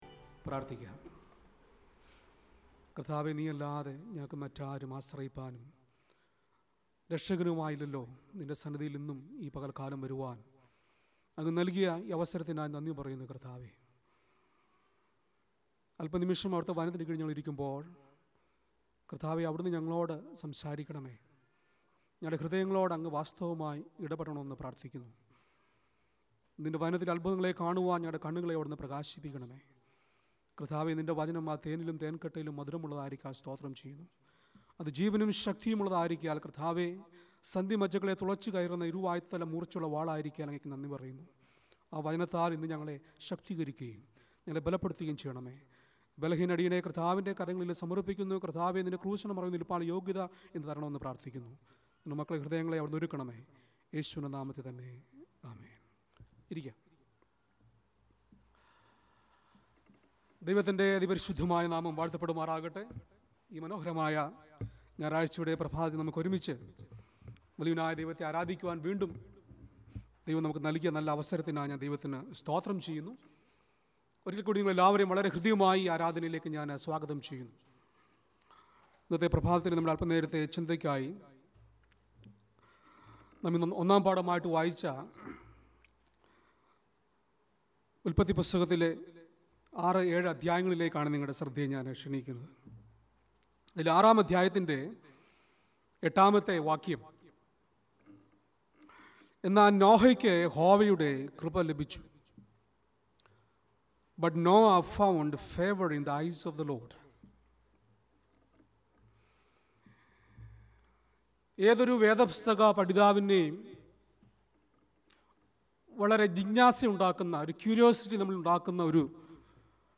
Weekly Sermon Podcast